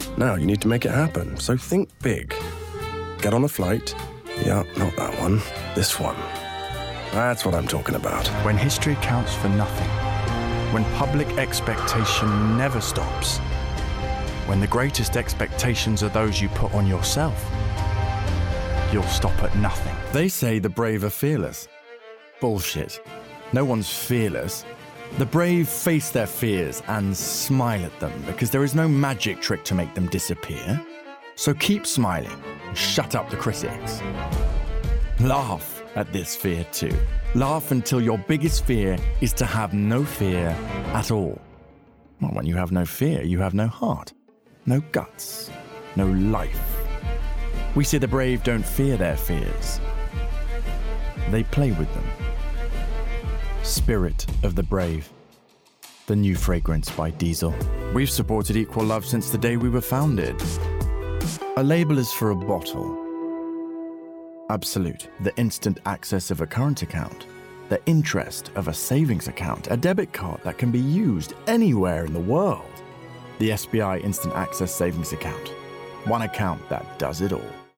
Commercial Showreel
Commercial, Cool, Deep, Uplifting, Soft, Strong
London, RP ('Received Pronunciation'), Straight, Street